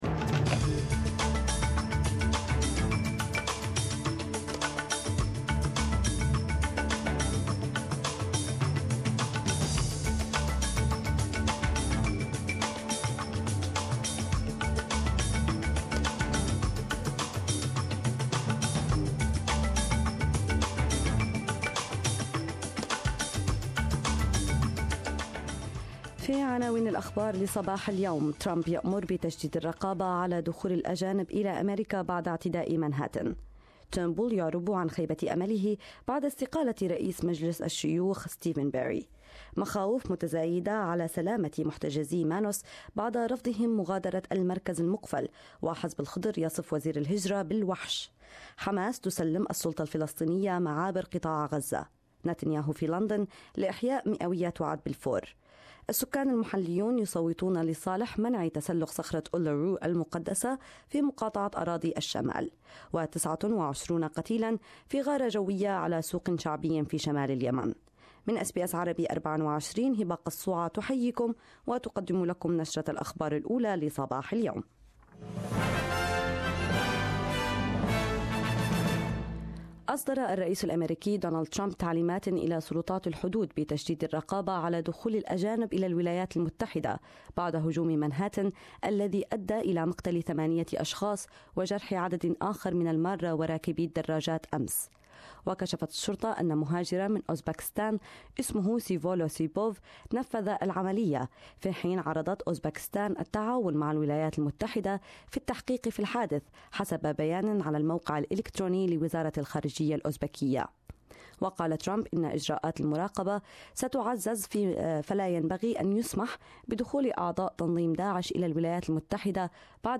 Arabic News 2/11/2017